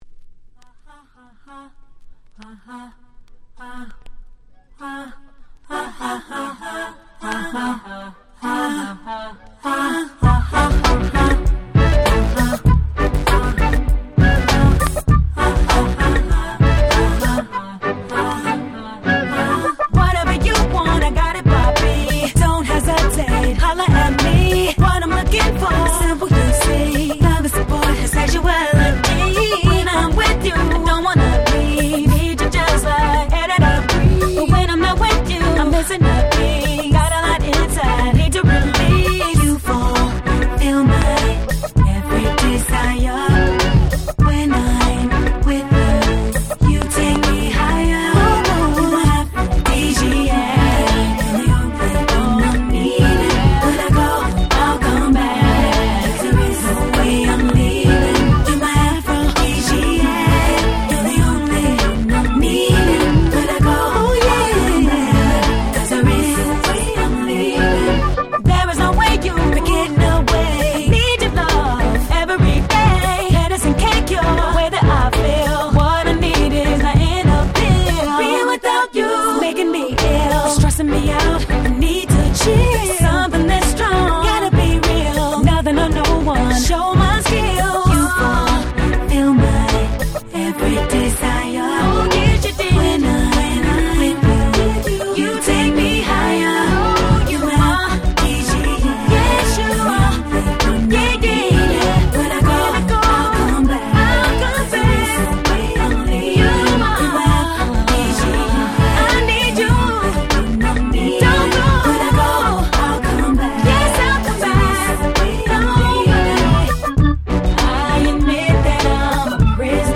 04' Smash Hit R&B !!
UrbanなMid Dancerで最高！！